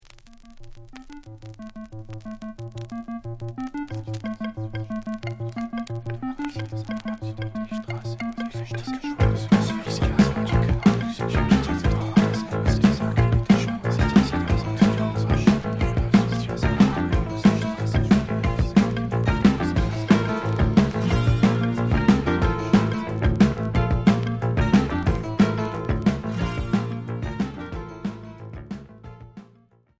Rock new wave Premier 45t retour à l'accueil